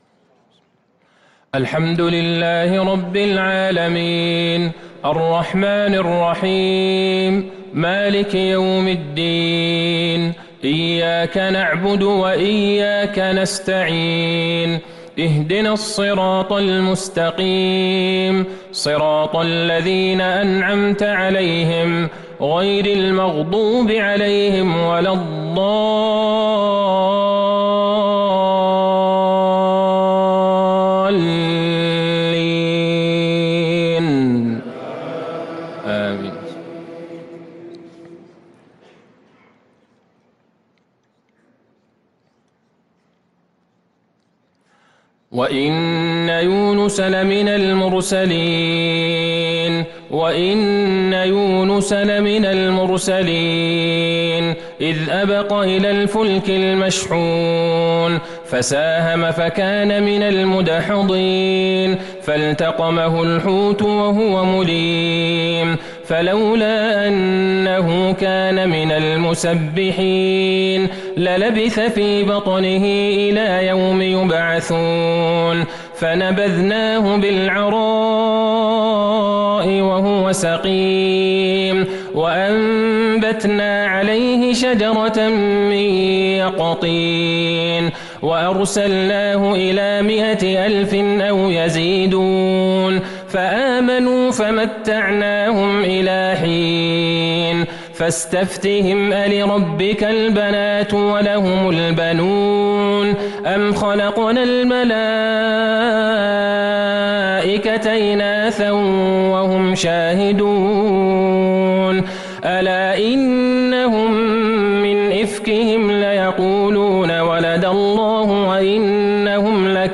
صلاة العشاء للقارئ عبدالله البعيجان 8 شوال 1443 هـ
تِلَاوَات الْحَرَمَيْن .